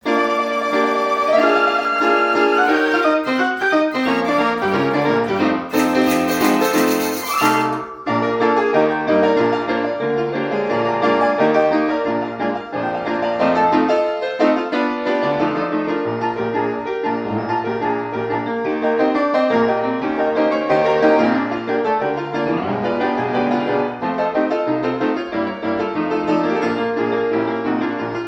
Beautiful music played on a 1920's Seeburg KT Orchestrion.